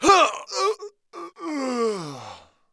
dead_2.wav